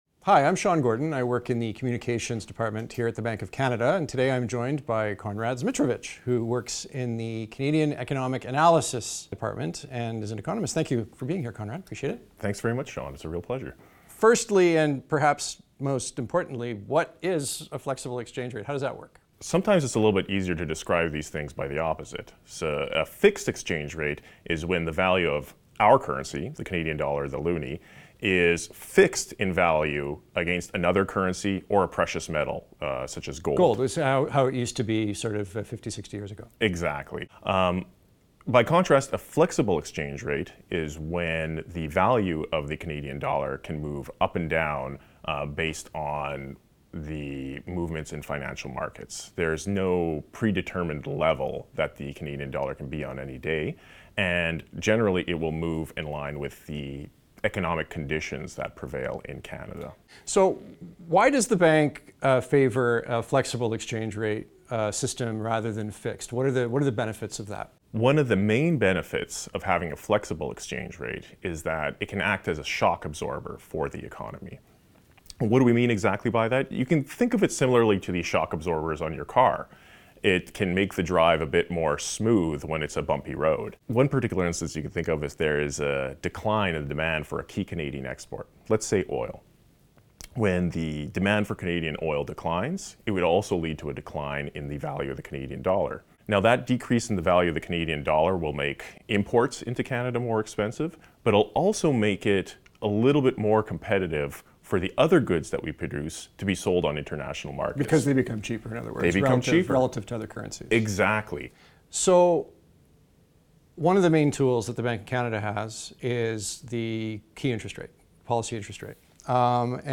Flexible_Exchange_Rate_Interview_Audio.mp3